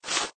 sandpaper_scrape_long.ogg